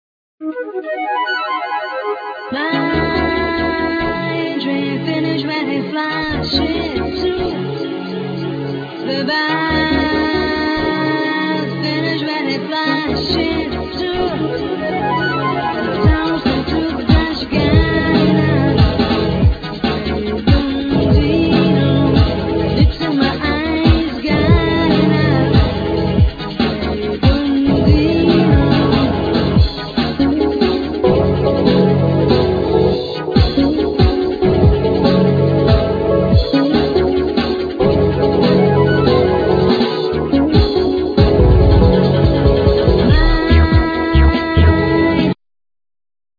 Synthsizer,Drum programming,Guitar
Sampling,Sax,Trumpet